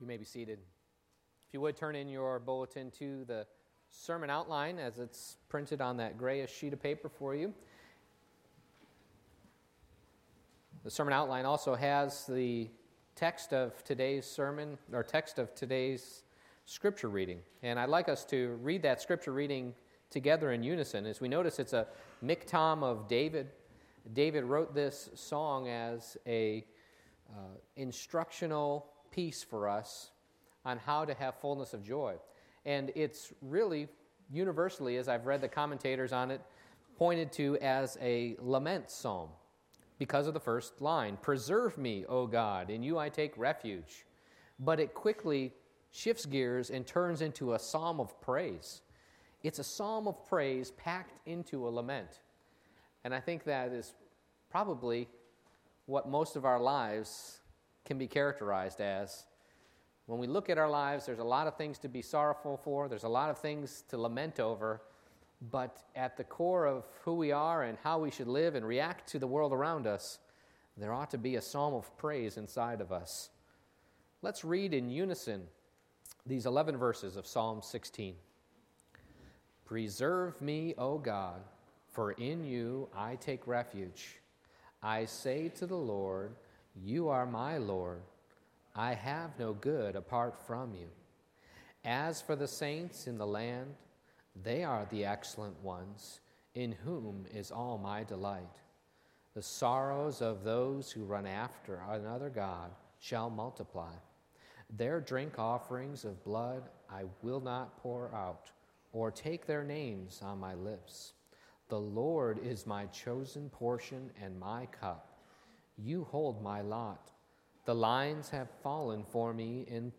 Psalm 16:1-11 Service Type: Morning Worship Where Can We Find Fullness of Joy?